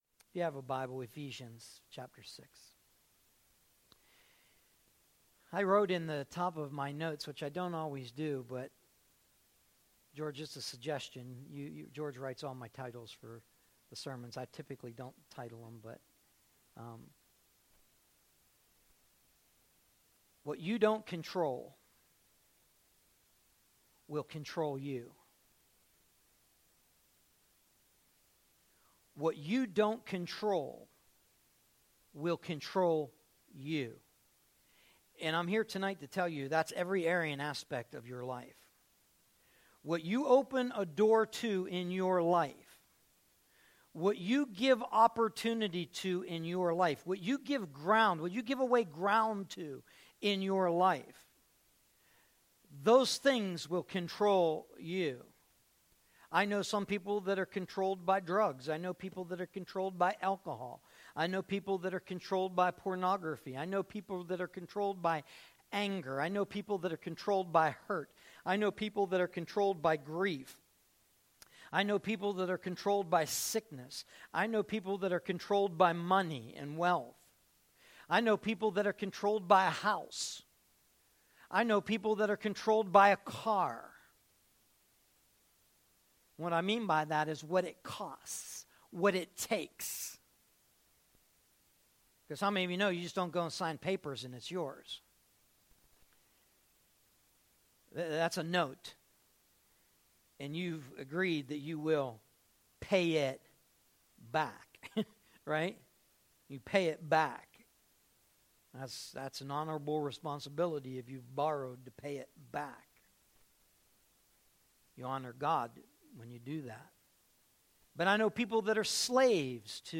“180613_0680.mp3” from TASCAM DR-05.